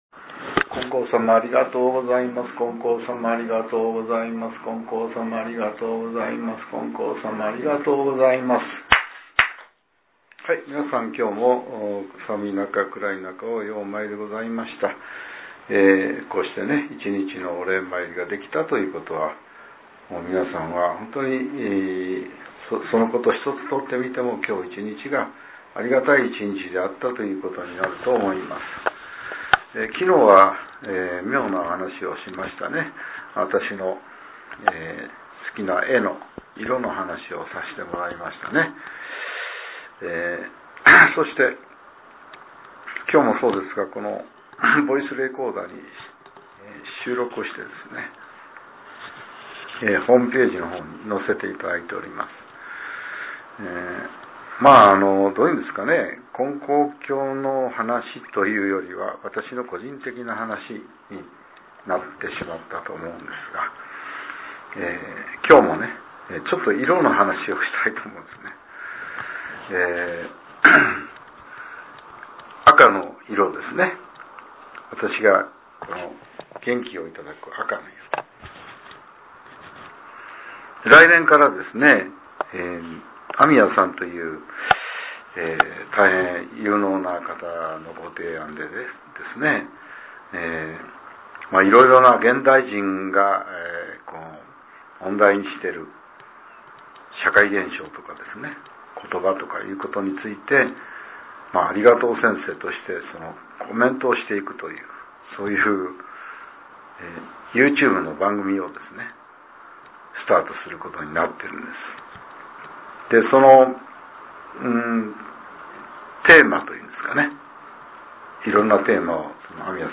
令和６年１２月１８日（夜）のお話が、音声ブログとして更新されています。